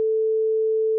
Sine Wave 1 sec.wav